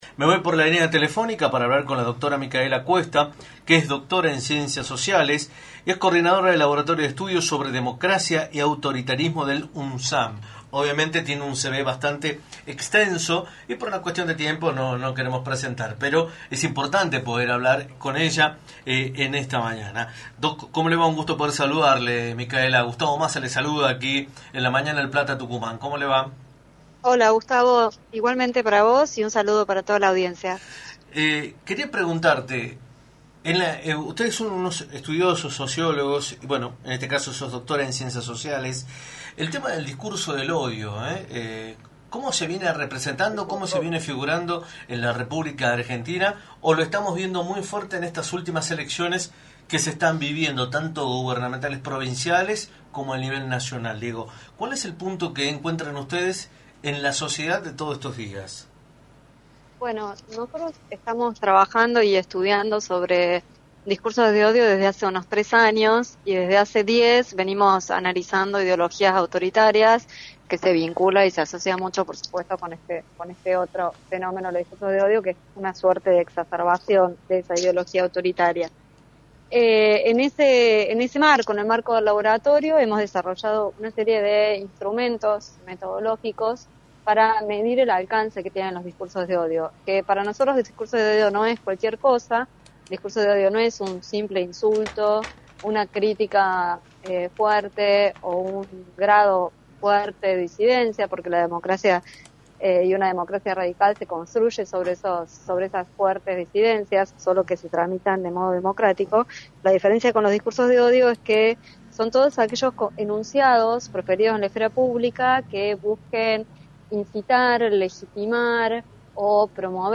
en entrevista para «La Mañana del Plata», por la 93.9